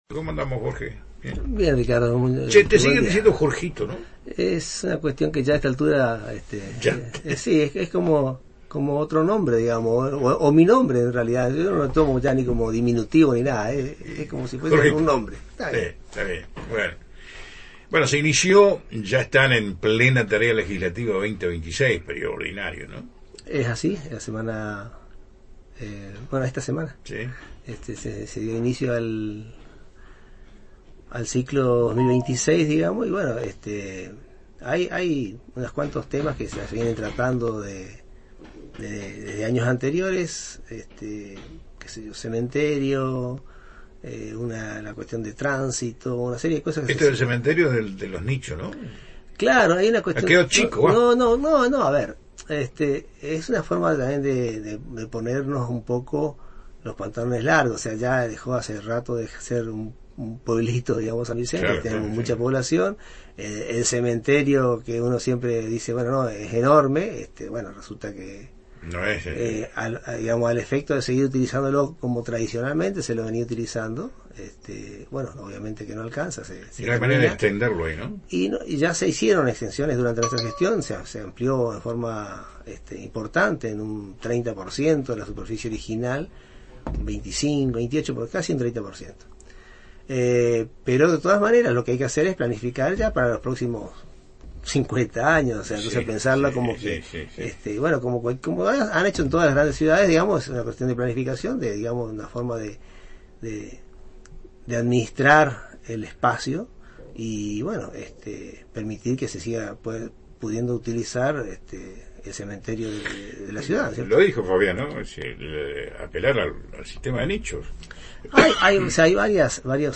NOTA-a-Jorge-Hasan-Pte-HCD-MSV.mp3